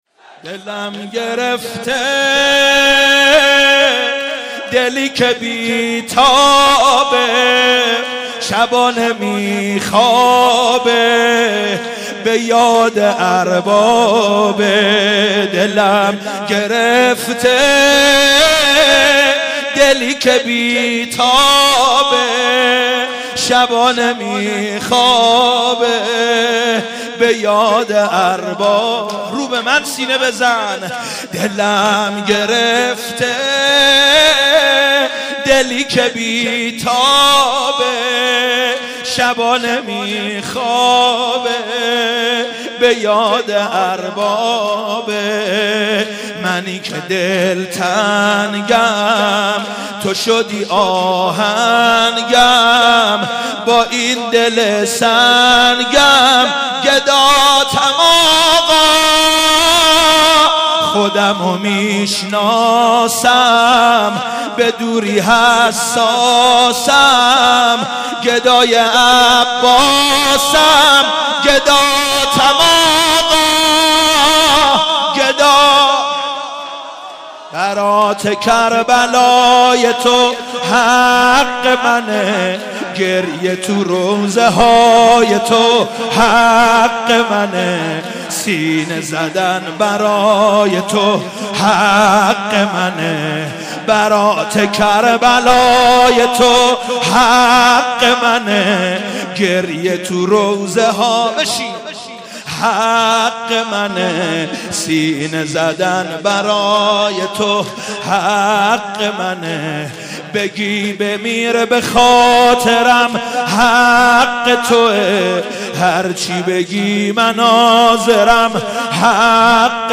مناسبت : وفات حضرت ام‌البنین سلام‌الله‌علیها
قالب : شور